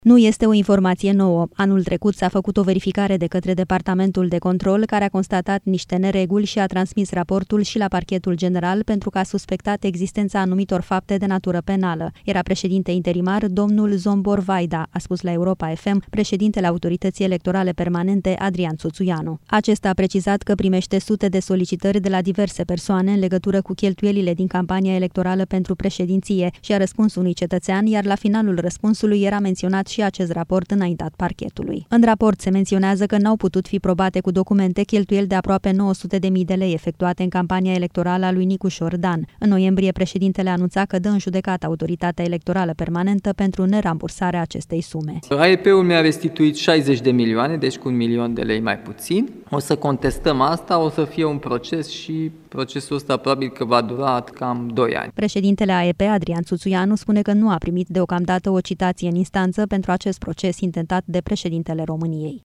Contactat de Europa FM, șeful AEP, Adrian Țuțuianu, spune că este vorba despre o sesizare mai veche, de anul trecut, semnată de fosta conducere a Autorității Electorale.